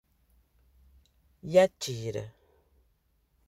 Dictée : Écoutez et écrivez le mot entendu.